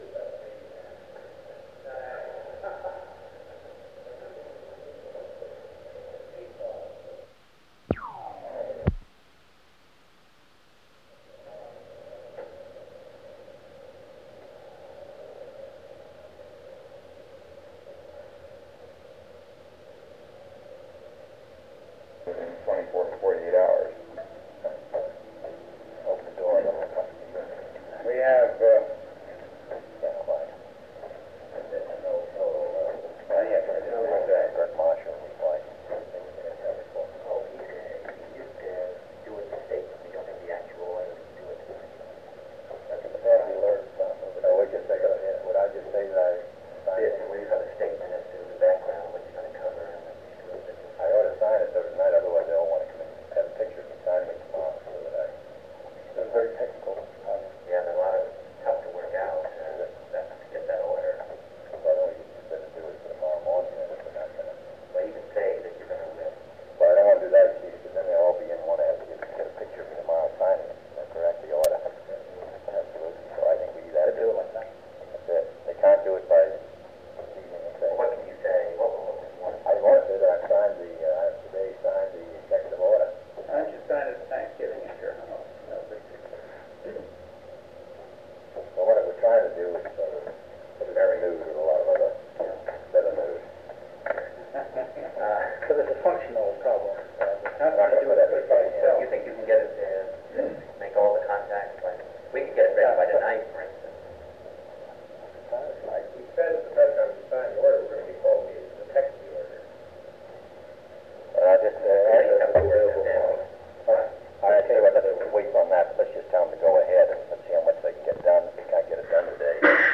Secret White House Tapes | John F. Kennedy Presidency Executive Committee Meeting of the National Security Council Rewind 10 seconds Play/Pause Fast-forward 10 seconds 0:00 Download audio Previous Meetings: Tape 121/A57.